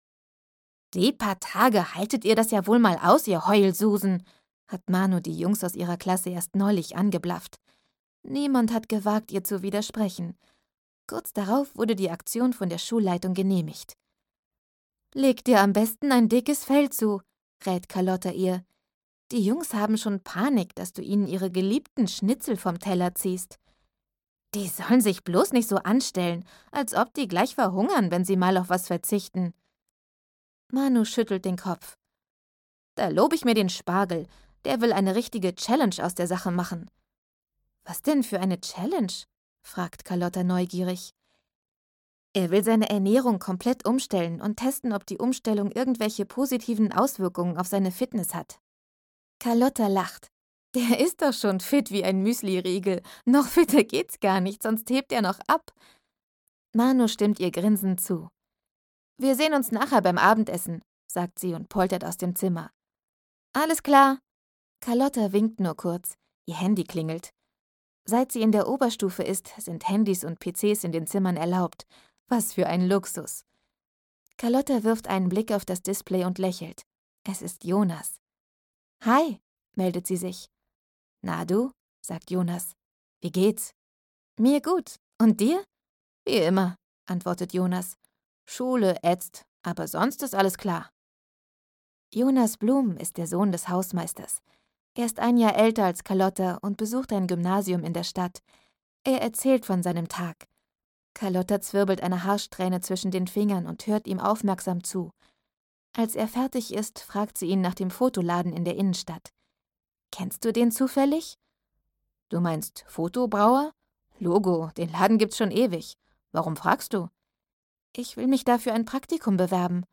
Carlotta 6: Carlotta - Herzklopfen im Internat - Dagmar Hoßfeld - Hörbuch